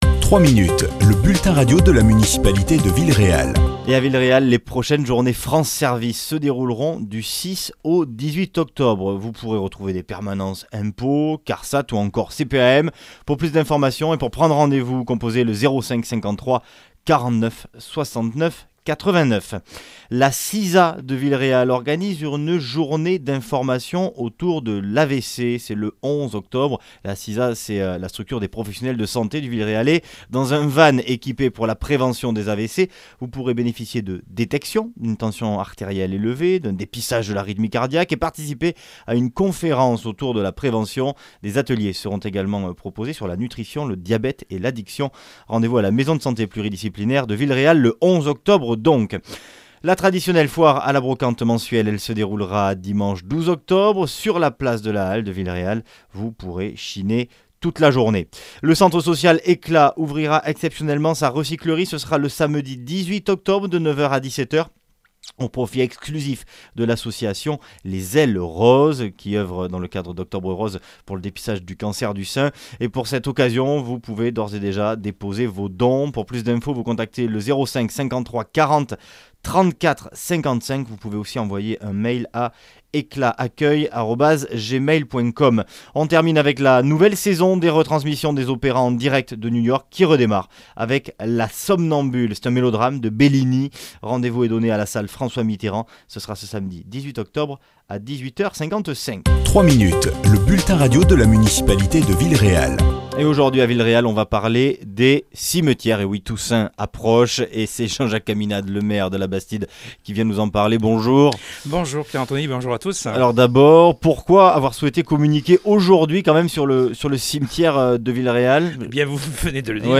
A quelques jours de la Toussaint, Jean-Jacques Caminade, maire de Villeréal, fait un point sur les cimetières de la commune.